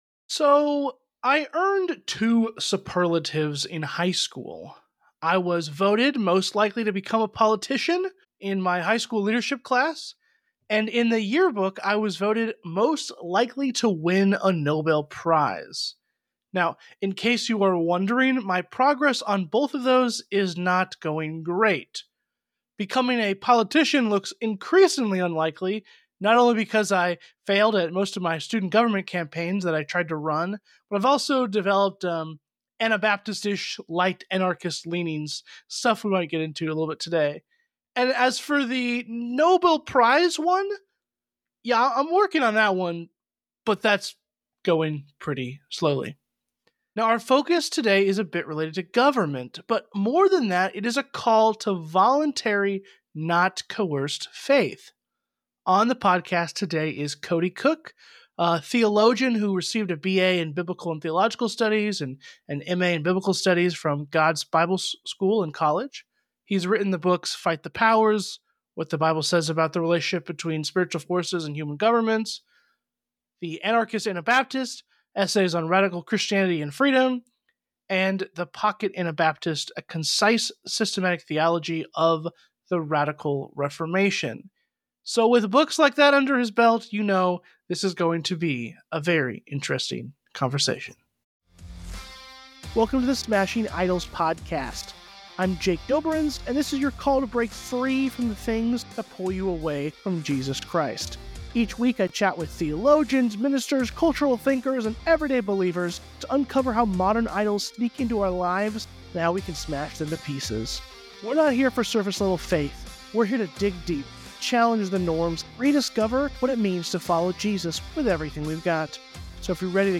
In this conversation on the Smashing Idols podcast